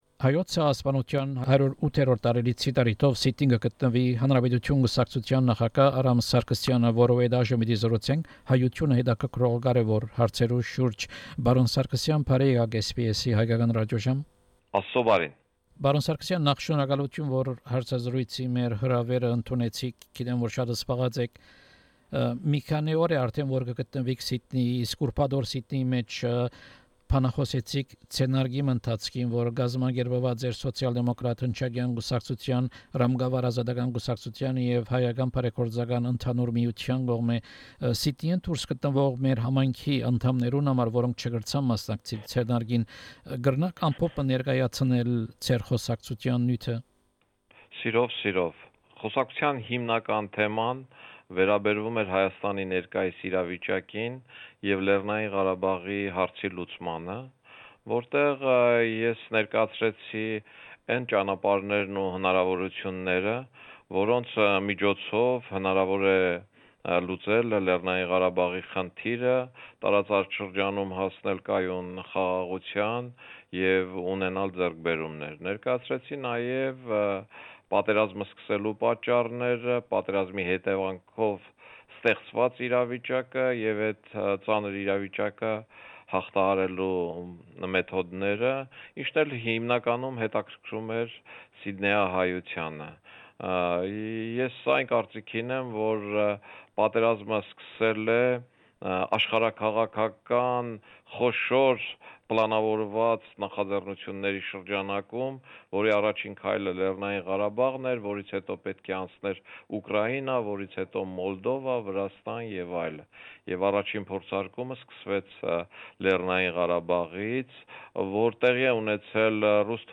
Interview with Mr Aram Sargsyan, head of the Republic Party of Armenia. Topics of discussion include his speech in Sydney at the event marking the 108th anniversary of the Armenian Genocide, current political and security situation in Armenia and Artsakh, the Collective Security Treaty Organization and Armenia, Russian policies in Armenia, the 27 October 1999 Armenian Parliament shooting and impression from his visit to Sydney.